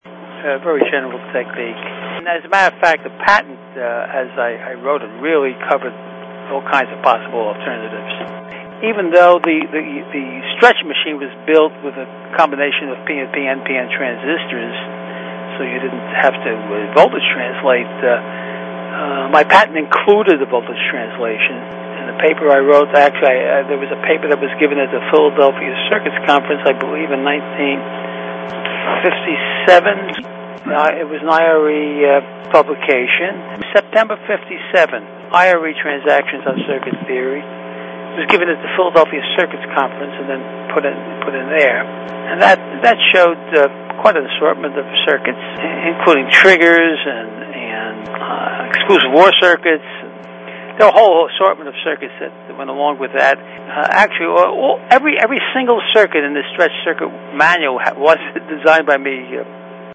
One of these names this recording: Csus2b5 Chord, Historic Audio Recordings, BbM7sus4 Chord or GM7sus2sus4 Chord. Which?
Historic Audio Recordings